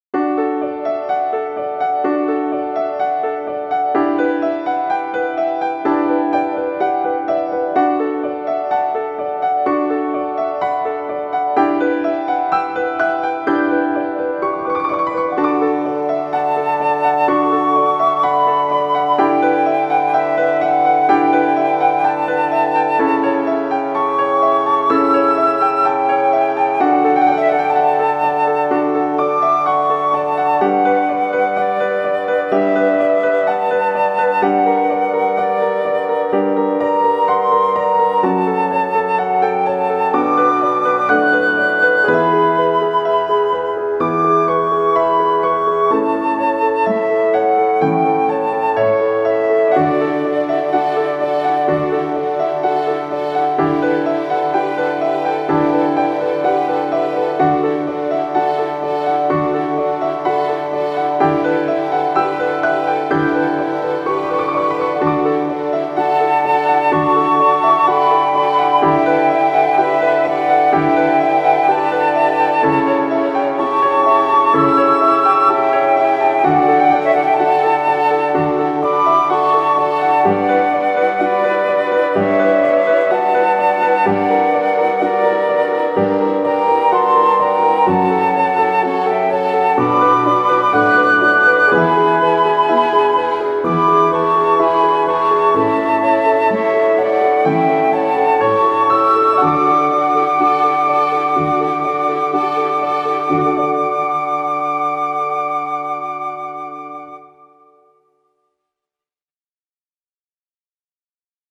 気持ち休まるピアノとフルートの旋律。